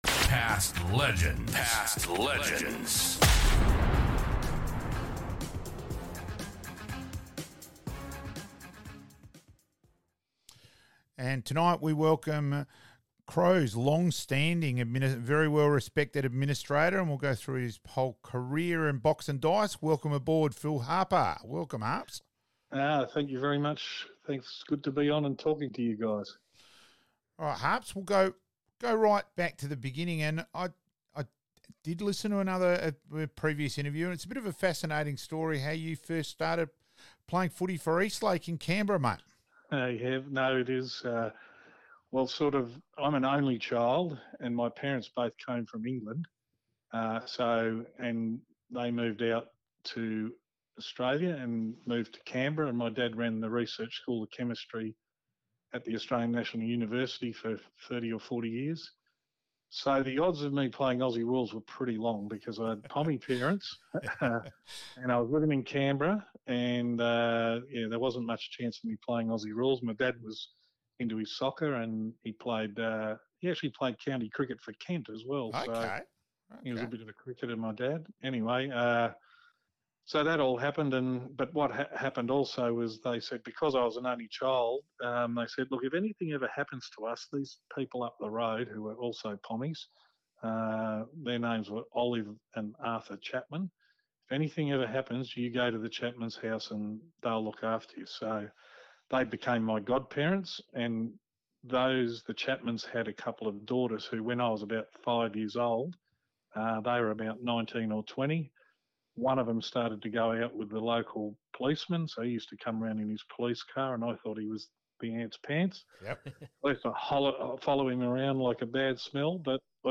Past Players Past Legends - Interview (only) with some of our special guests